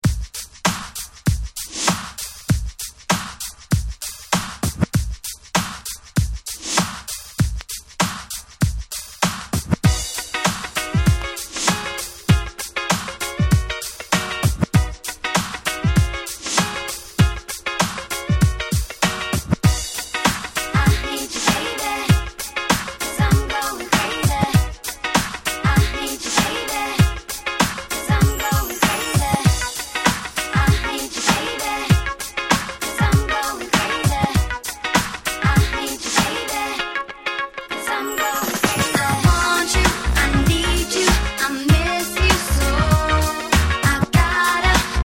楽曲的にも『わかってる感』満載のキャッチーで使い易い非常に良い1曲。